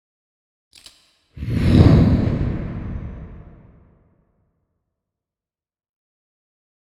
Flame Ignition
acetylene burn burner burning combustion explosion fire flame sound effect free sound royalty free Memes